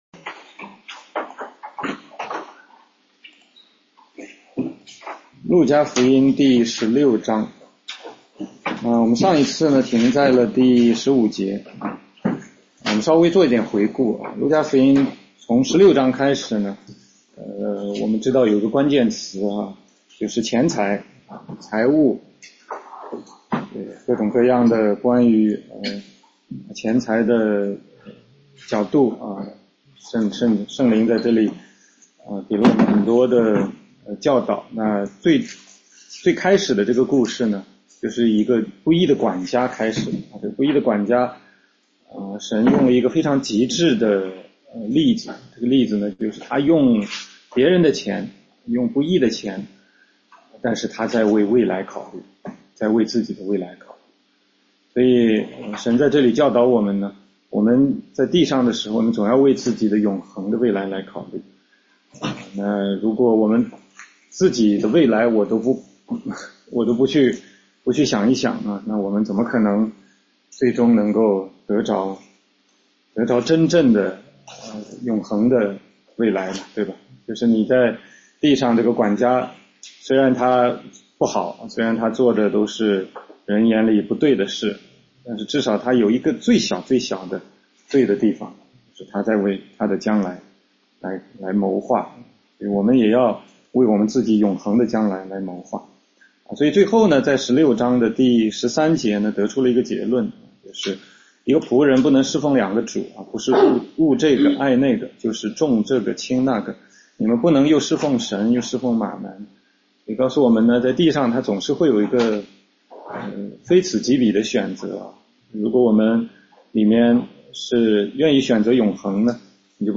16街讲道录音 - 路加福音16章16-24节：律法与神的国